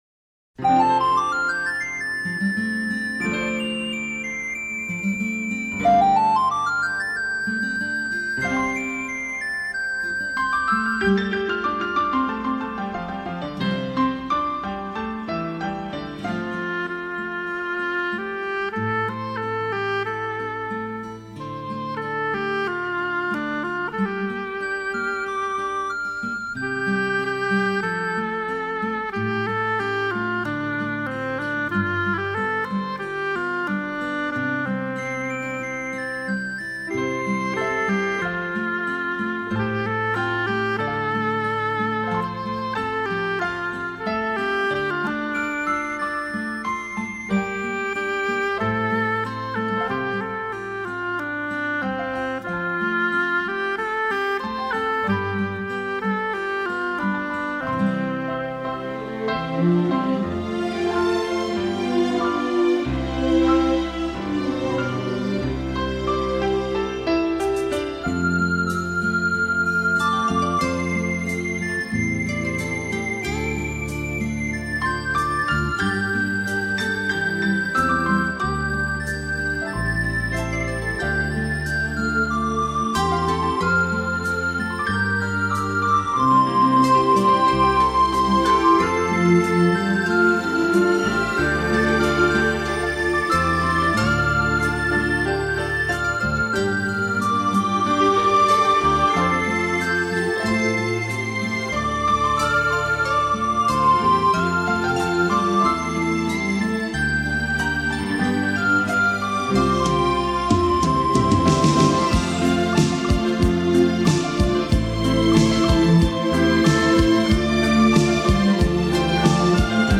时而波澜 时而温暖 时而静谧
轻音乐